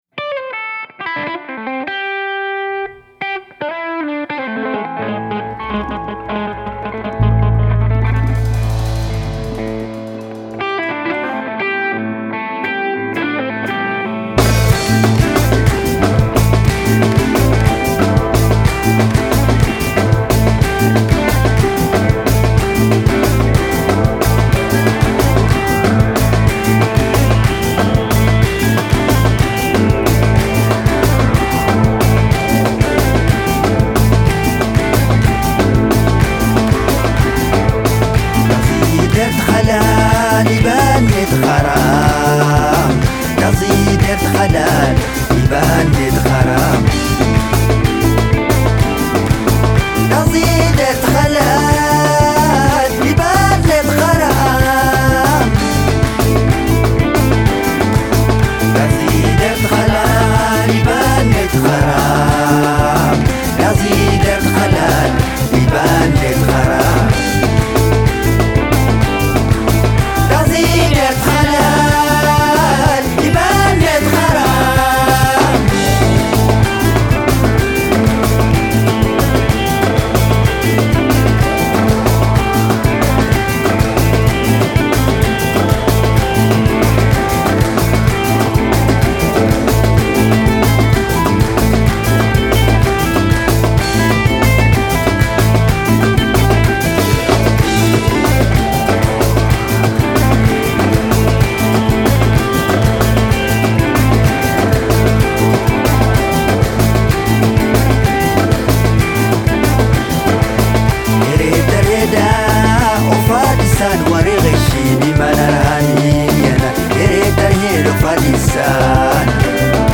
le guitariste et chanteur nigérien
electric, hypnotic desert blues